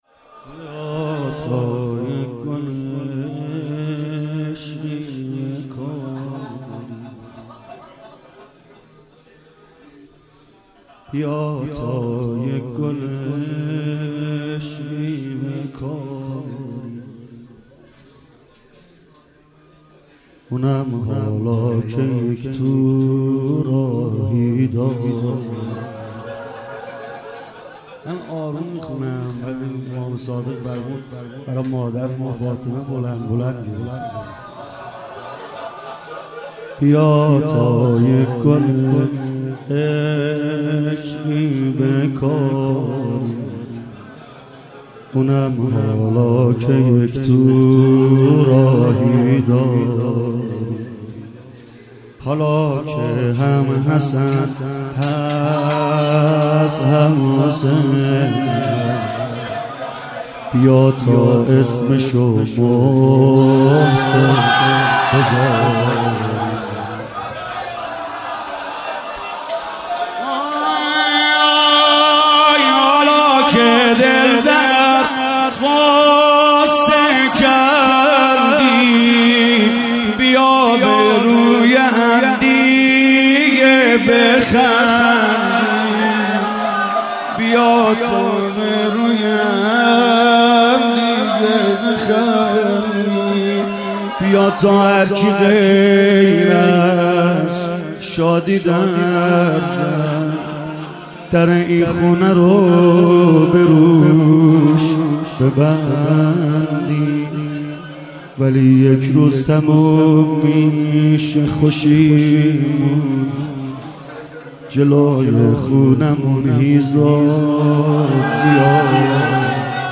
11جمادی الاول 95 - روضه - بیا تا یه گل عشقی بکاریم